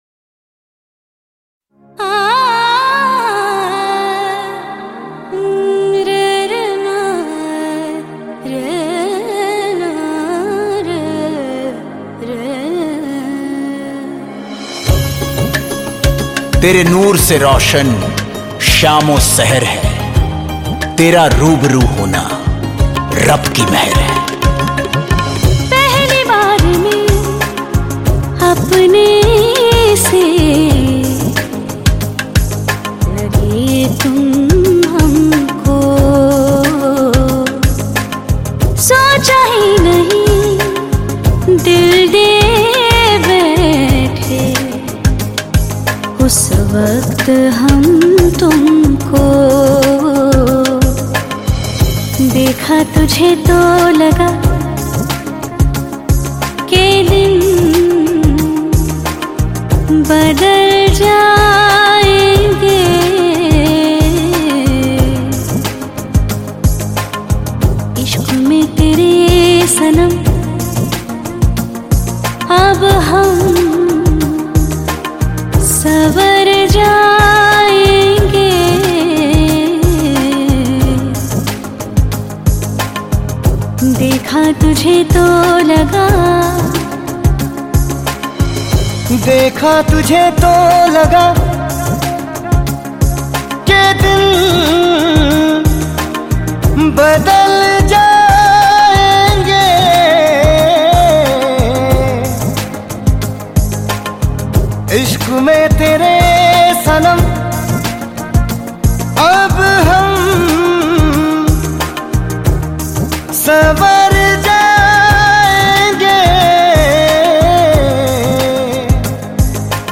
Hindi Pop Album Songs